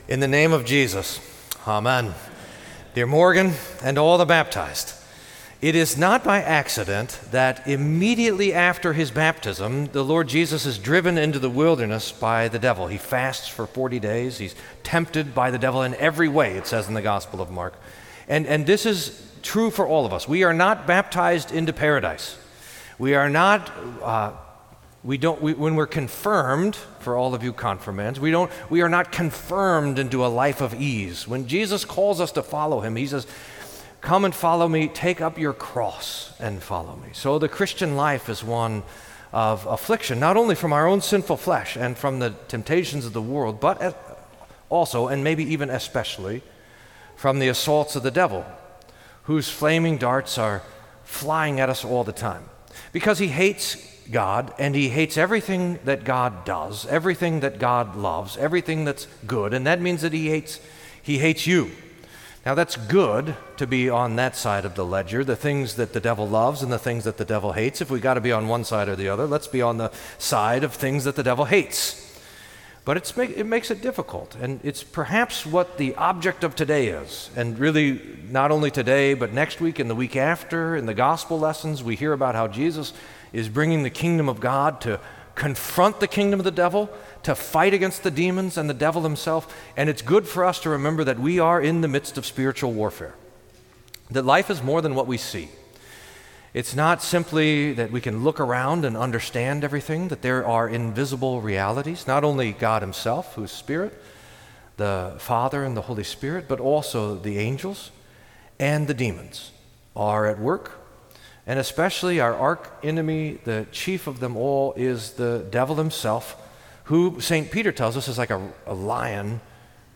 Sermon for First Sunday in Lent